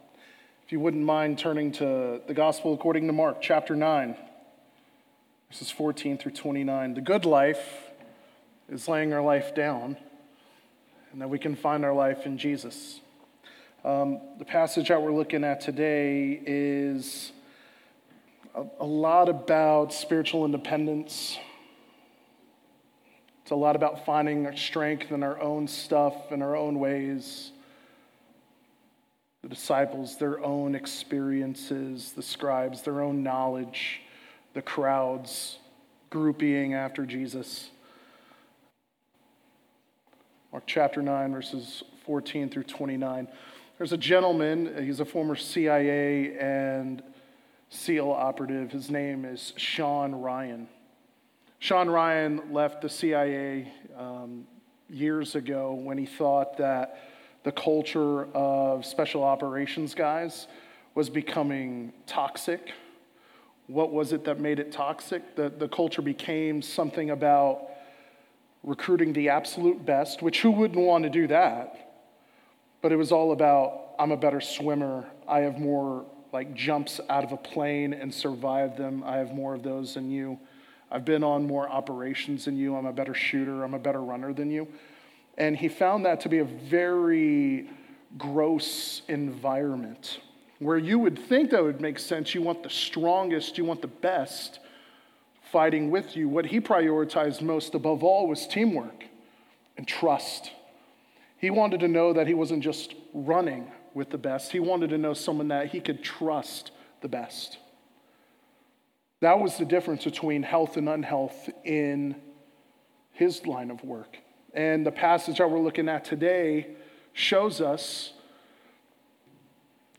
Sermons | Hazelwood Baptist Church